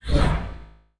Robotic Servo Notifcation.wav